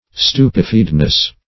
Stupefiedness \Stu"pe*fied`ness\, n.
stupefiedness.mp3